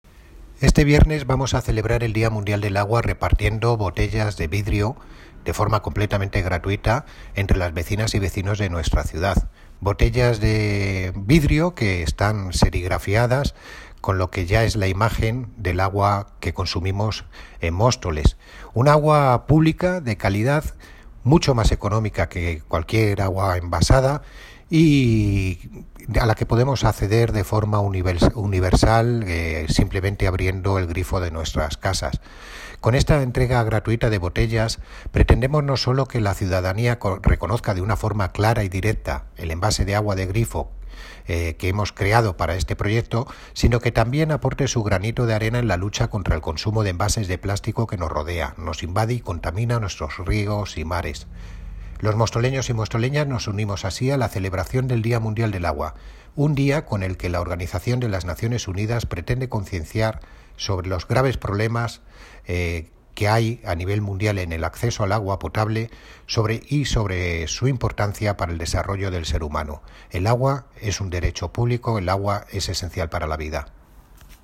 Audio - Miguel Ángel Ortega (Concejal de Medio Ambiente, Parques y Jardines y Limpieza Viaria) Sobre día mundial del agua